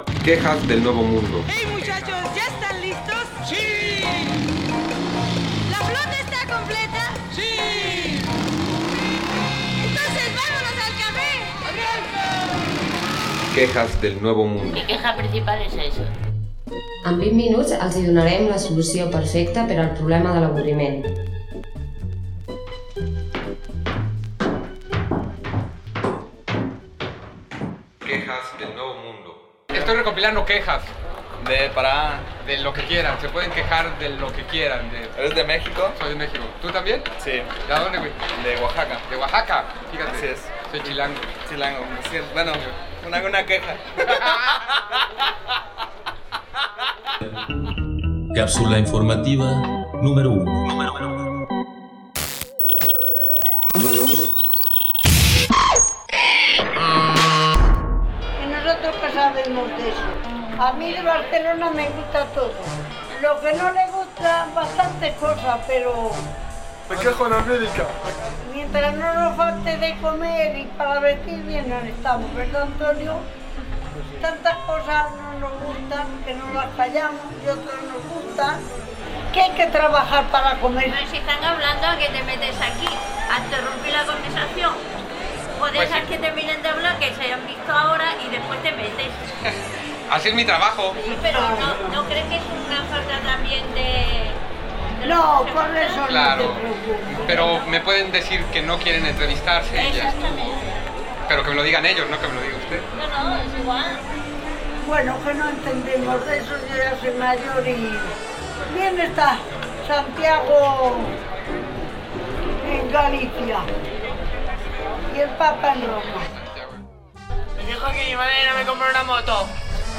Recopilació de queixes diverses de la ciutadania, amb opinions recollides a Barcelona